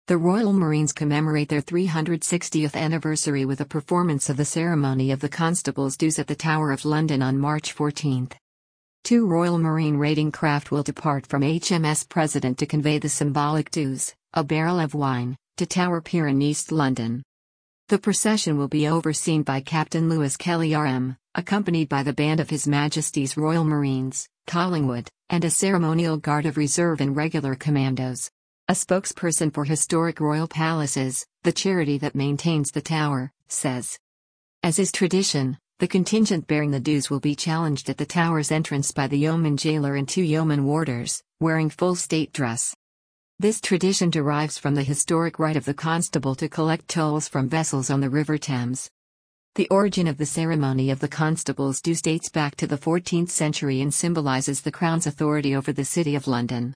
Listen to this article powered by AI